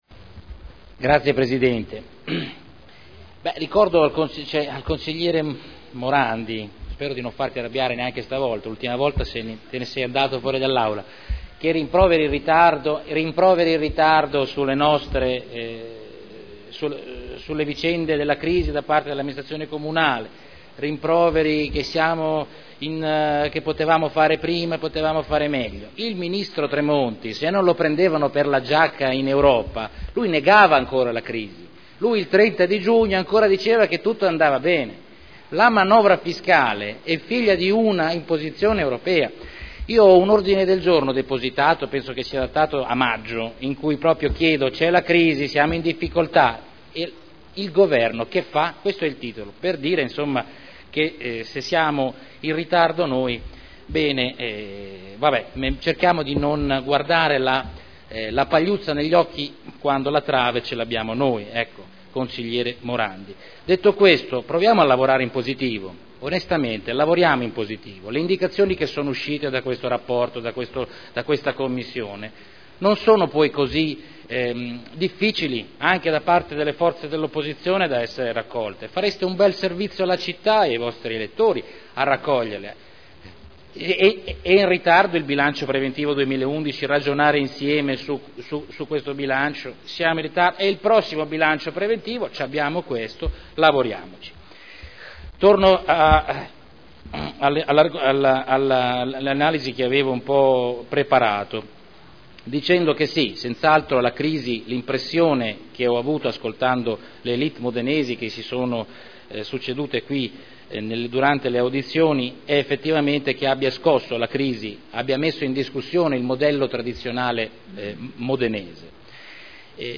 Salvatore Cotrino — Sito Audio Consiglio Comunale